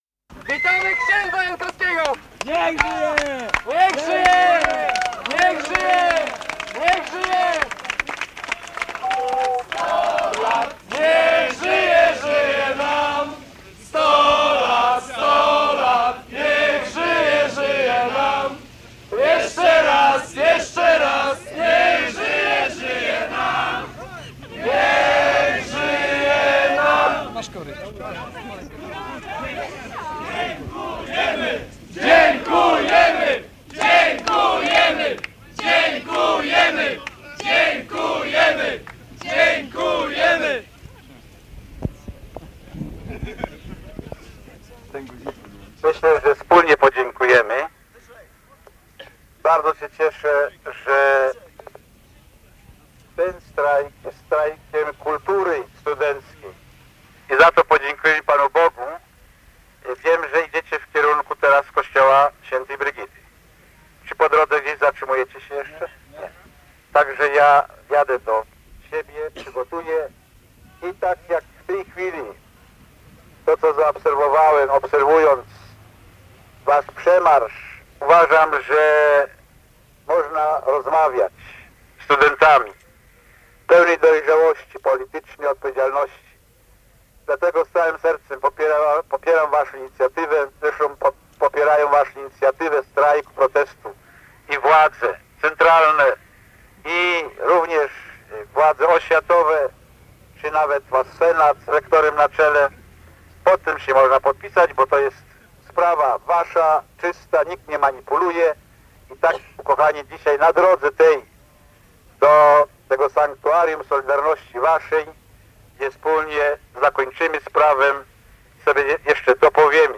Manifestacja studentów uczelni Trójmiasta [dokument dźwiękowy] - Pomorska Biblioteka Cyfrowa